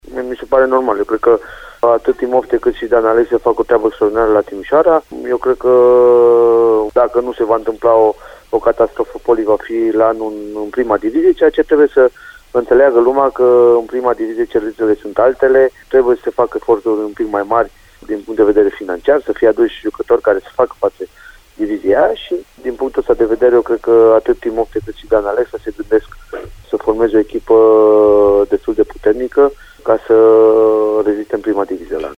Într-un inteviu acordat postului nostru de radio, singurul tehnician român din Spania a precizat că Dan Alexa şi Ion Timofte fac o treabă extrordinară la Timişoara şi că Poli nu poate rata promovarea.